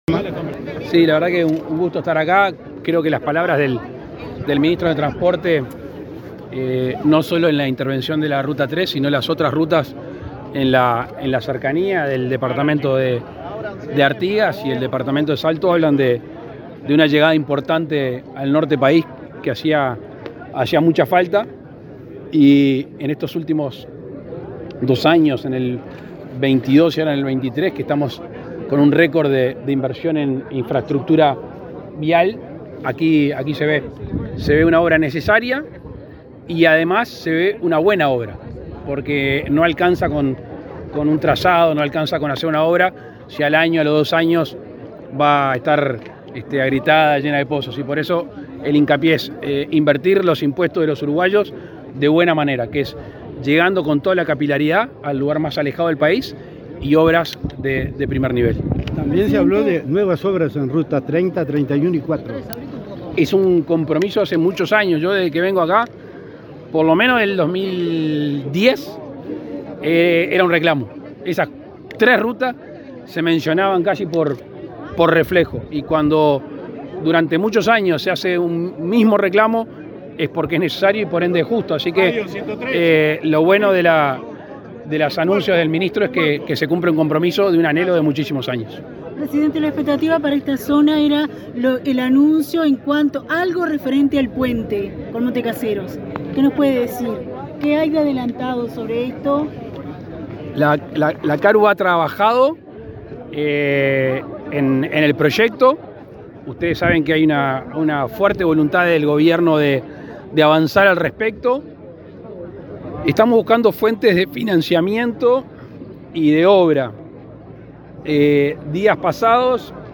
Declaraciones del presidente Lacalle Pou a la prensa
Declaraciones del presidente Lacalle Pou a la prensa 05/09/2023 Compartir Facebook X Copiar enlace WhatsApp LinkedIn Este martes 5, el presidente de la República, Luis Lacalle Pou, encabezó el acto de inauguración de obras viales en el tramo de la ruta 3 comprendido entre Salto y Bella Unión. Luego dialogó con la prensa.